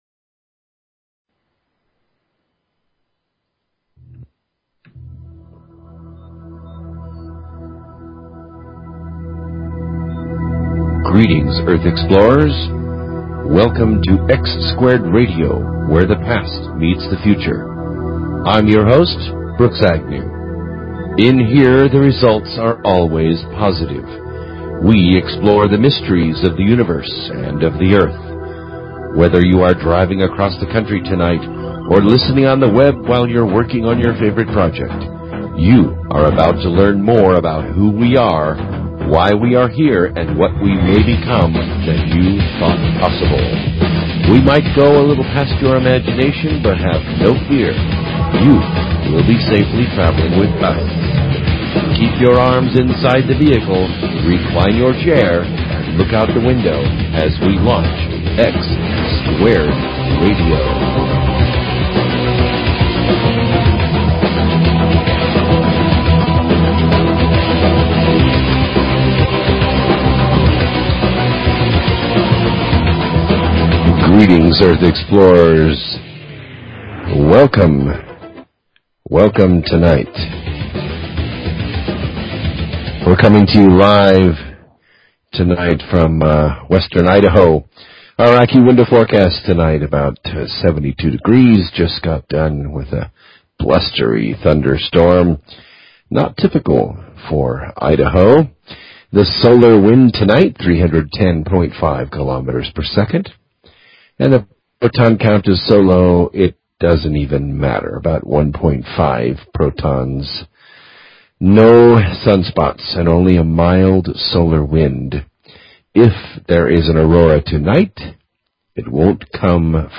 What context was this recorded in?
Live open lines.